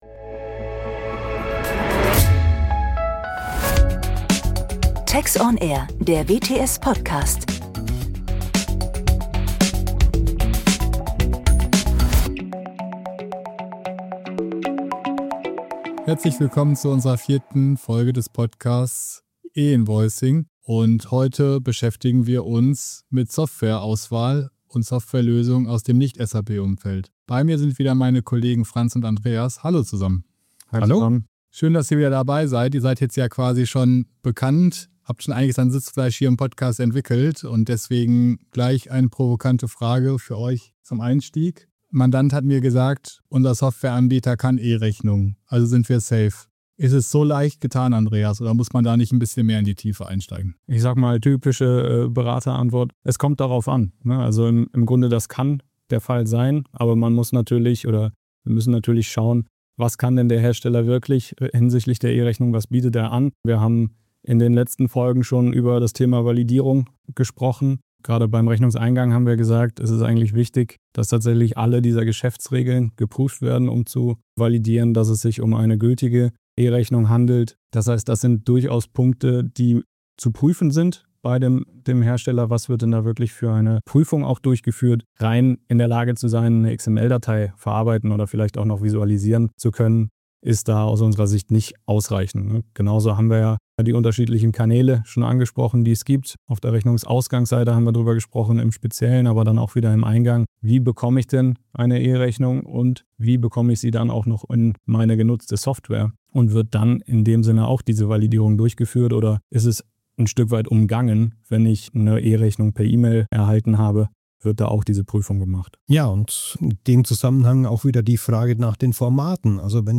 Die Podcast-Reihe „Einfach E-Rechnung“ beleuchtet im Rahmen von kurzweiligen Expertengesprächen neben den aktuellen rechtlichen Entwicklungen und Rahmenbedingungen in Deutschland auch viele technische und prozessuale Fragestellungen im Rechnungseingangs- und Rechnungsausgangsprozess.